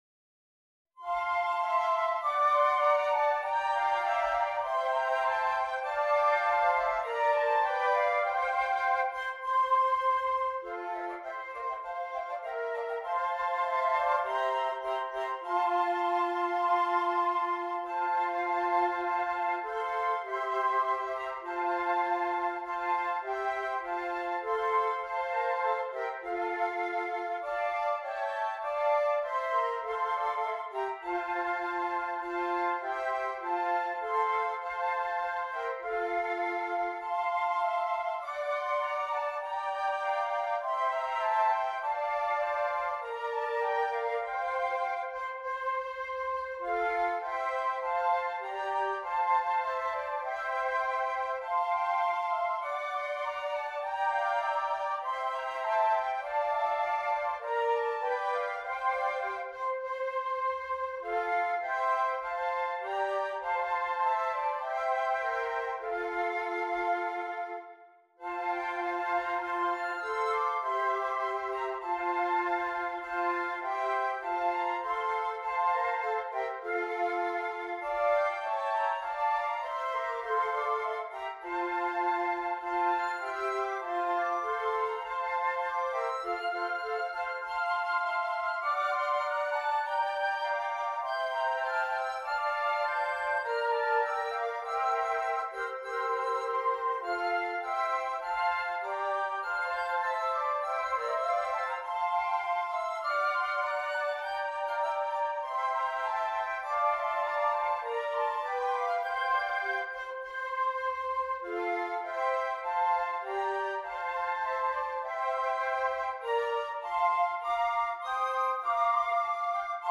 6 Flutes
Traditional French Carol